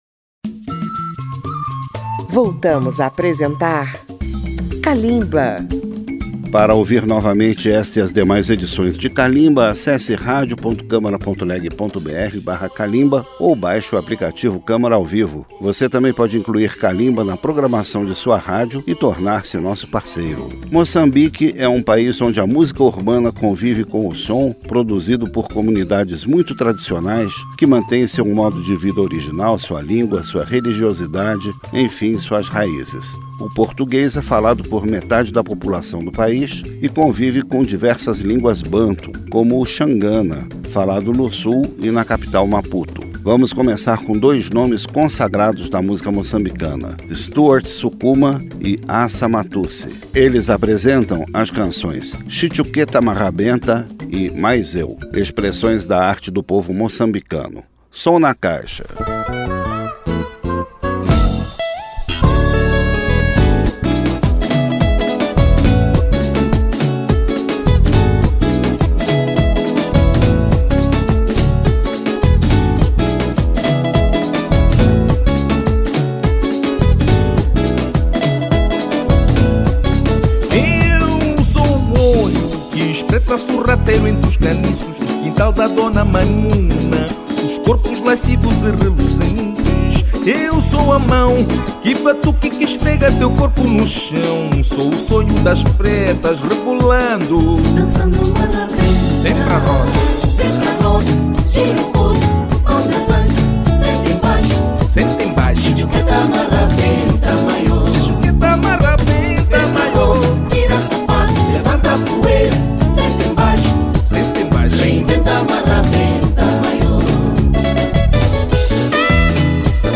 Programas da Rádio Câmara
Para celebrar essa data, um elenco da primeira linha da música moçambicana, entre artistas consagrados e novas revelações.
Kalimba mostra a evolução da música dos países africanos e apresenta a diversidade étnica e regional do continente, a partir de seus diversos estilos: a música tradicional, a música urbana, religiosa, instrumental, eletrônica, etc.